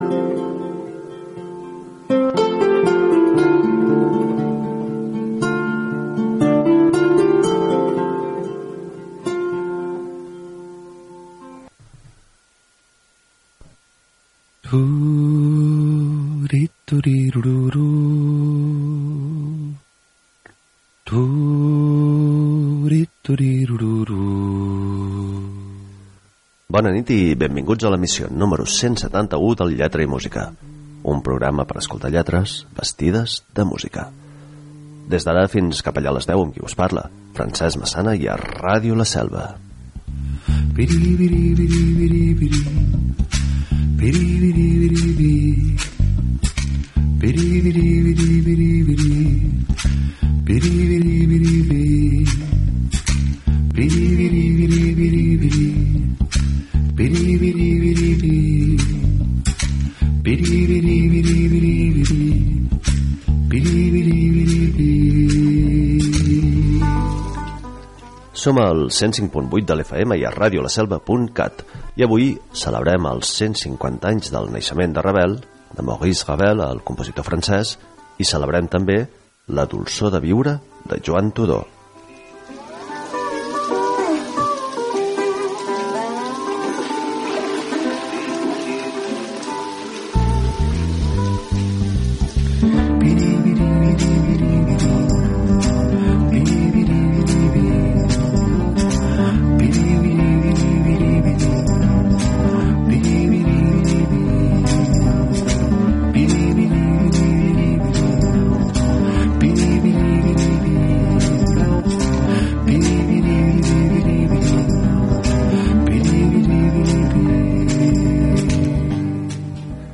Un programa per escoltar lletres vestides de música. I per llegir textos nus. I per deixar-nos tapar amb músiques sense lletra.